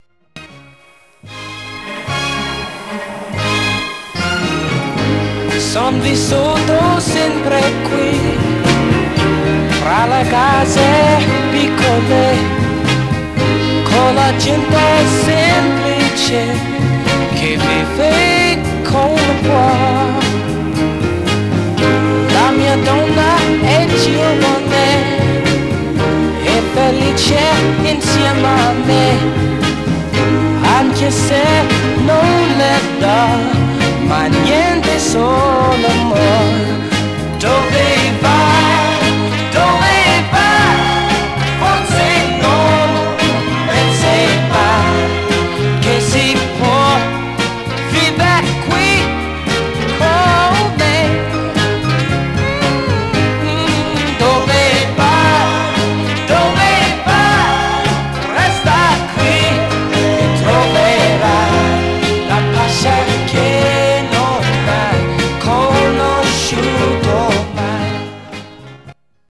Surface noise/wear Stereo/mono Mono
Soul